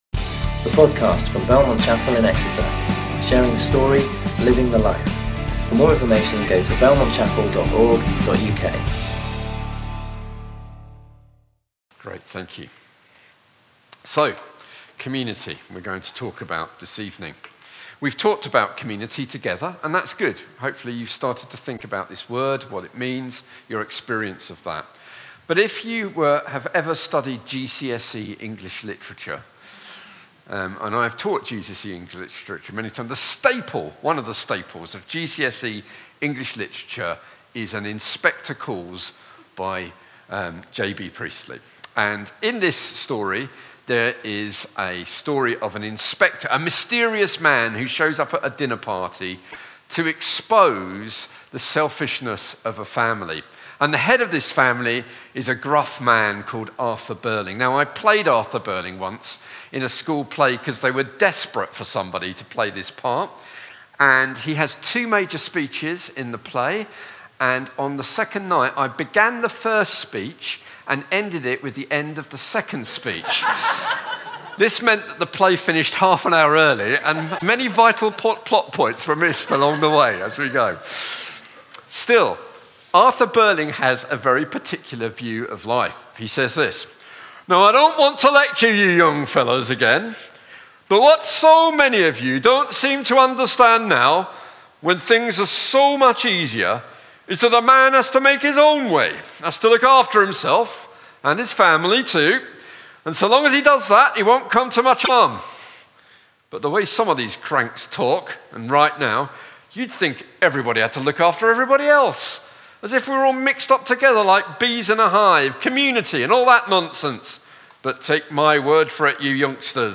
Belmont Exeter's weekly morning and evening service talks.